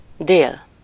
Ääntäminen
Synonyymit sud Ääntäminen France: IPA: /mi.di/ Haettu sana löytyi näillä lähdekielillä: ranska Käännös Ääninäyte 1. dél Suku: m .